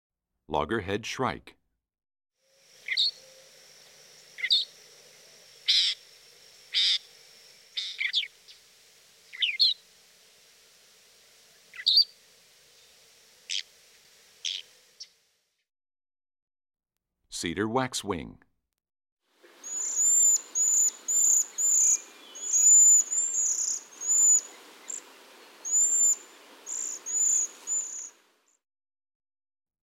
Index of /songs/Animals/Birds/Bird Songs Eastern-Central
25 Loggerhead,Cedar Waxwing.mp3